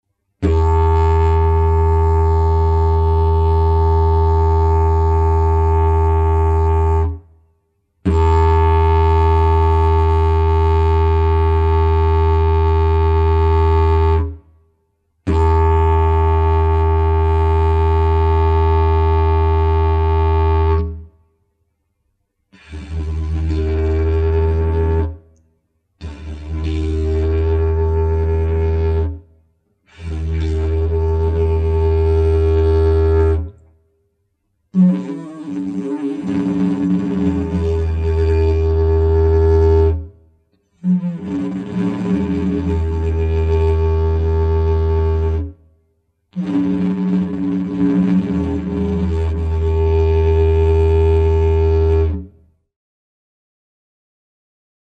Il didjeridoo è un aerofono ad ancia labiale.
Sample n°1 contiene: nota base
Il didgeridoo utilizzato nei sample è in Mi b.